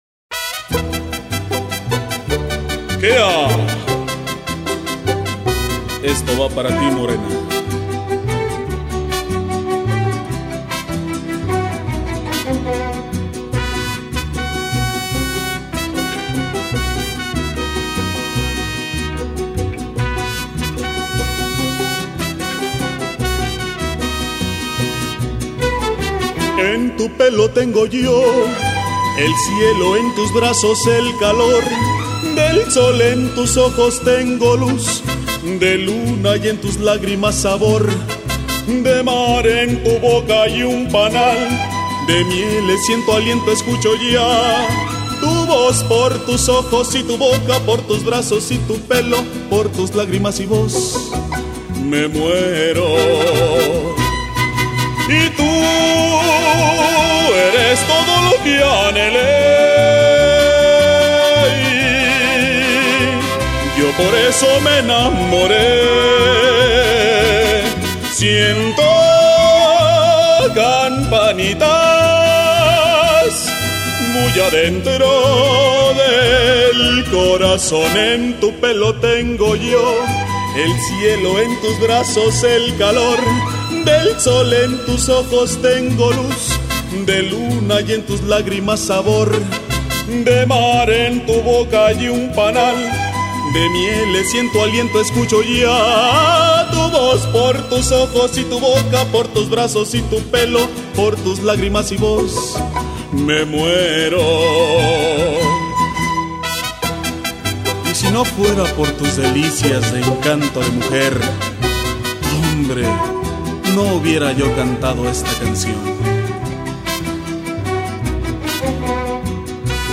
Música Ranchera
(Mexican Country Music - Literally 'Ranch Music')